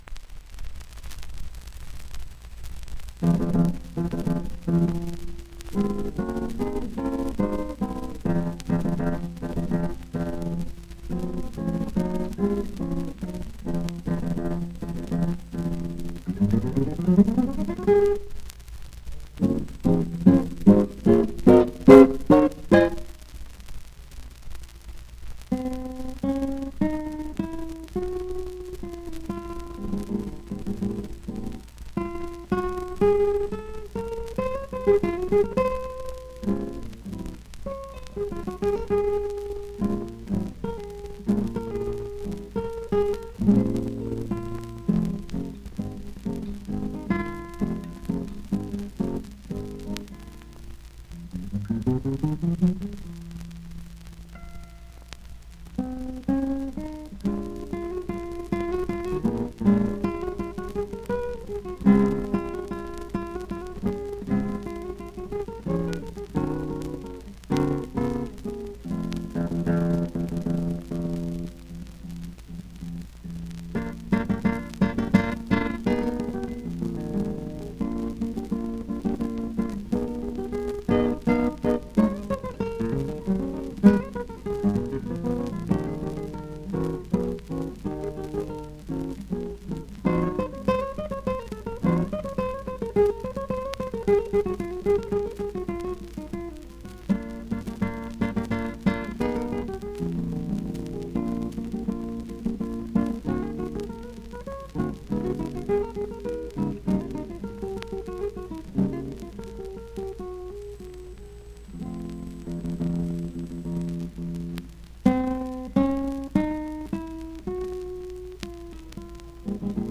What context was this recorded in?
1 disco : 78 rpm ; 30 cm Intérprete